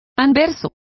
Complete with pronunciation of the translation of obverses.